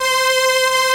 ARP STR ENS.wav